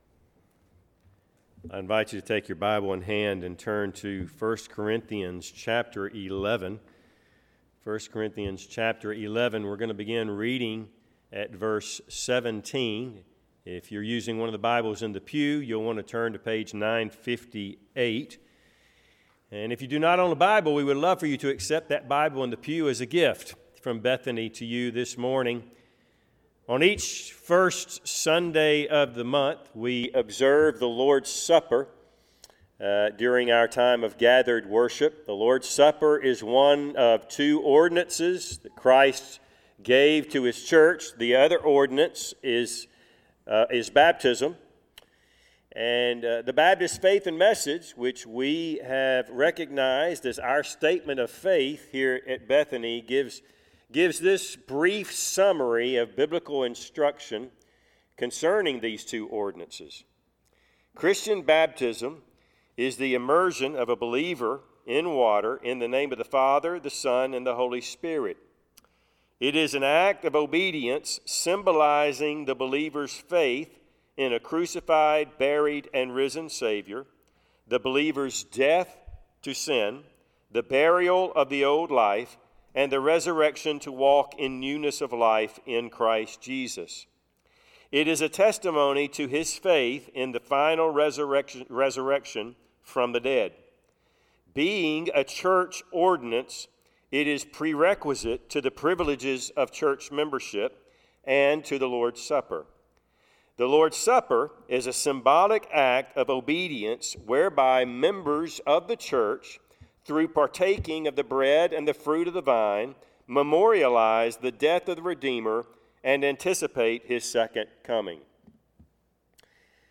Service Type: Sunday AM Topics: Church ordinances , The Lord's Supper